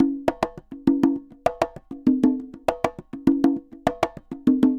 Congas_Samba 100_7.wav